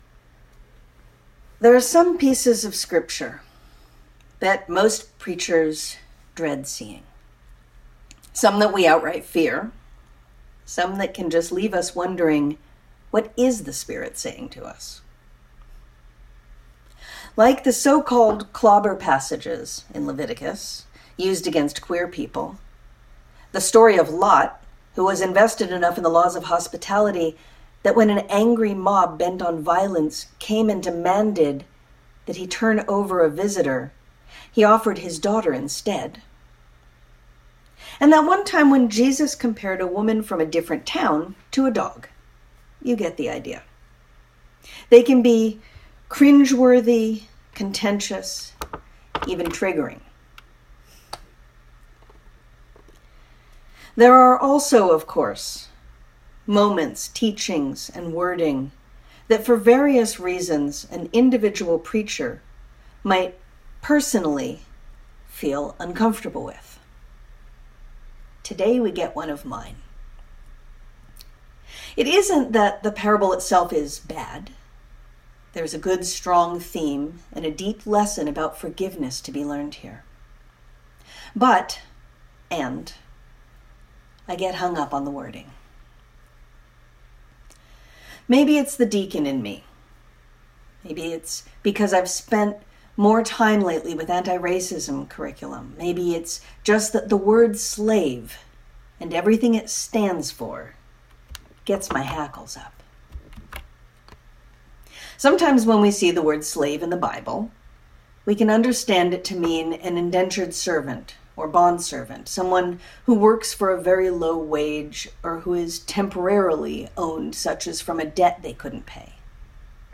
Sermon-10k-Talents-and-Grace_audio.mp3